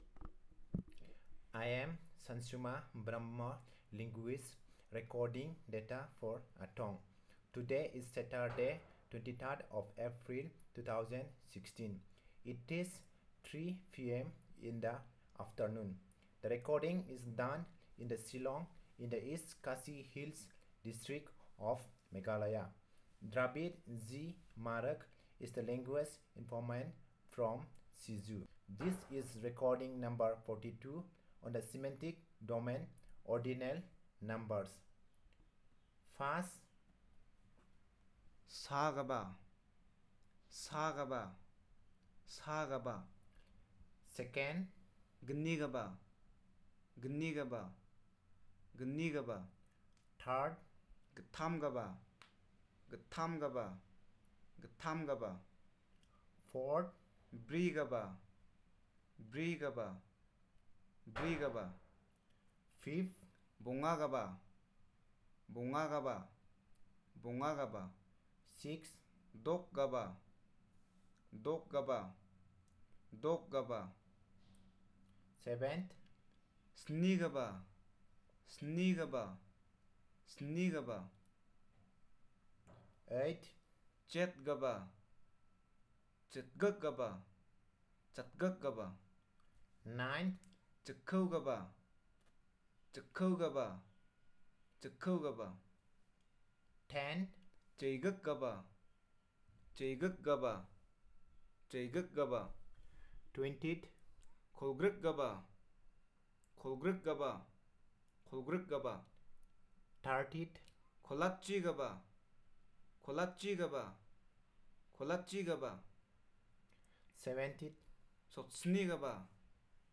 Elicitation of words about ordinal numbers